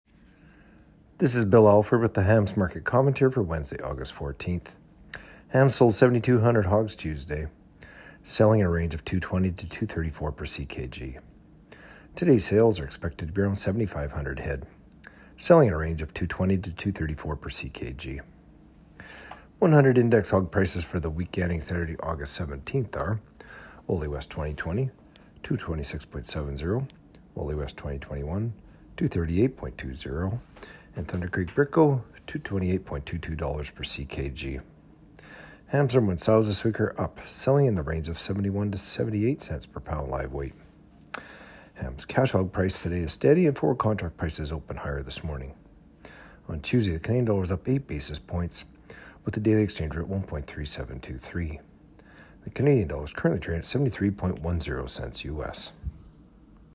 Market-Commentary-Aug.-14-24.mp3